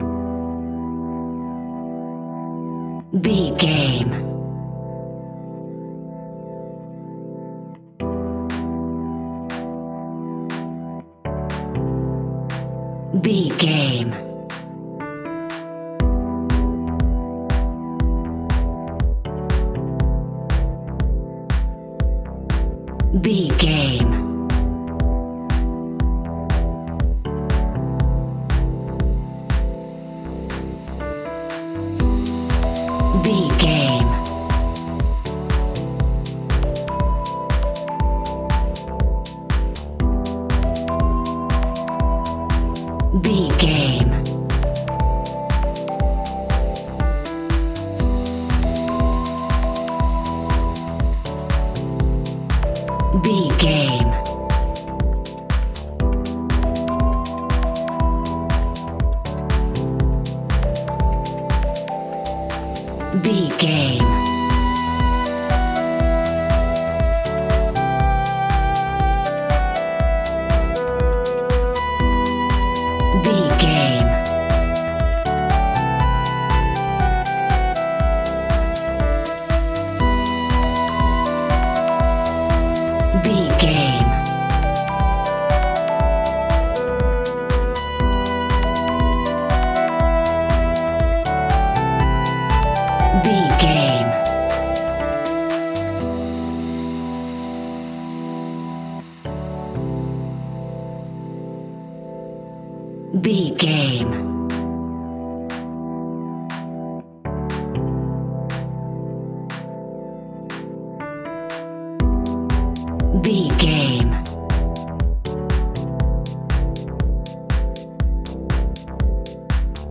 Aeolian/Minor
uplifting
driving
energetic
repetitive
bouncy
synthesiser
drum machine
electro house
progressive house
instrumentals
synth leads
synth bass